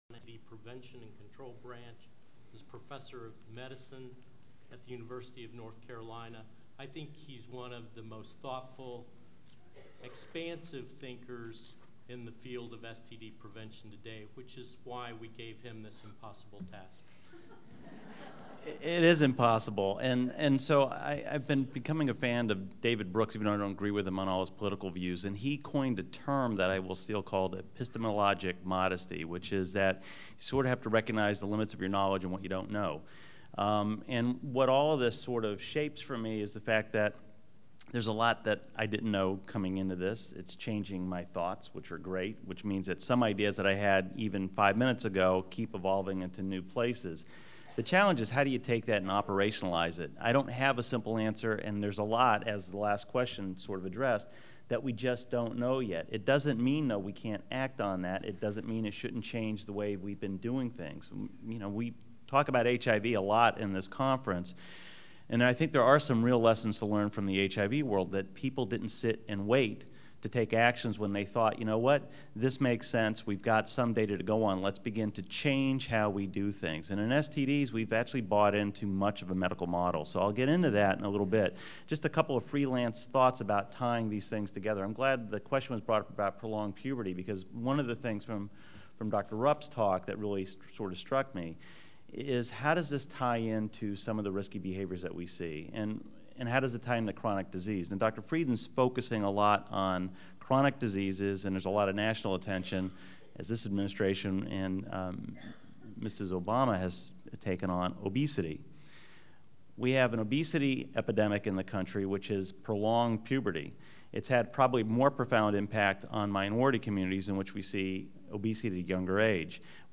Recorded presentation
Symposium